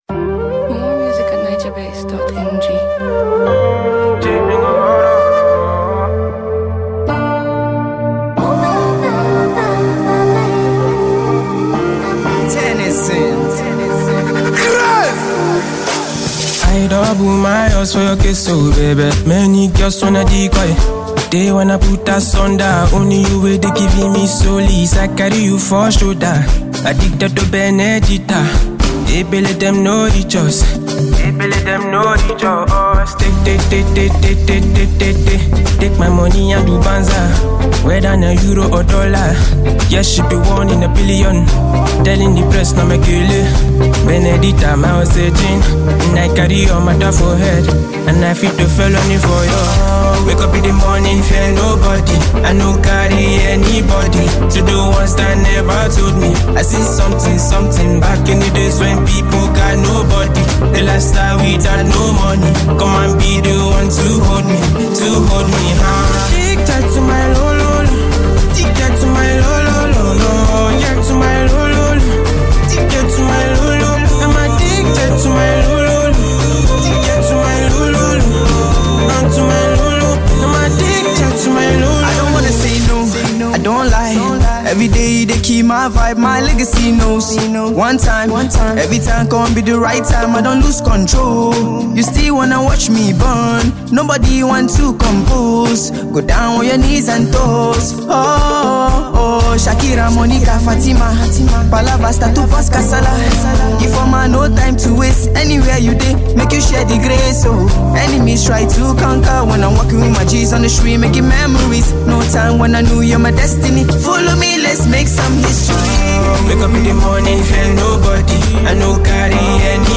is a smooth and emotional vibe